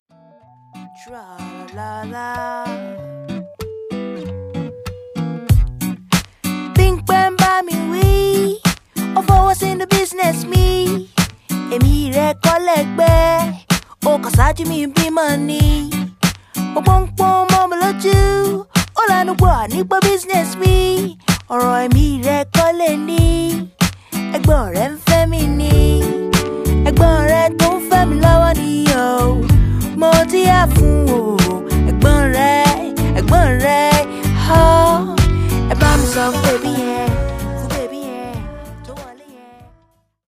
“魂の歌声”“忘れられないメロディ“